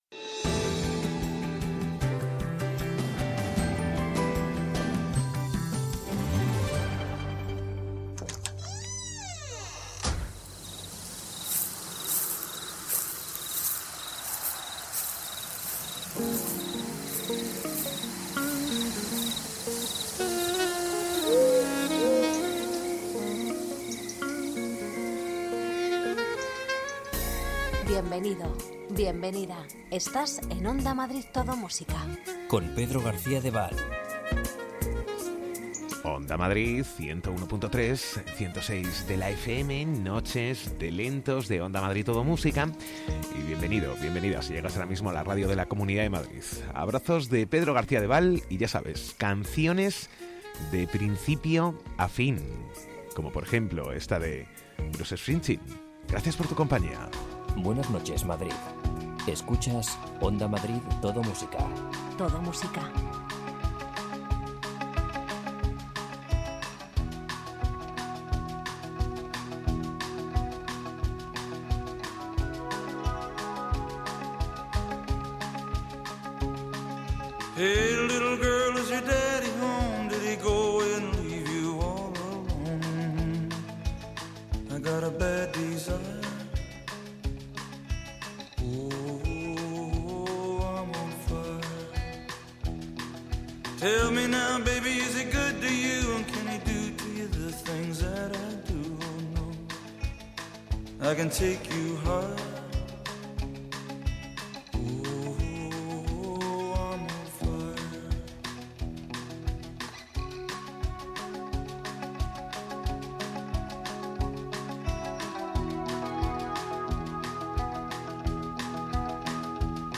Los mejores lentos
Ritmo tranquilo, sosegado, sin prisas...
La magia de la radio de noche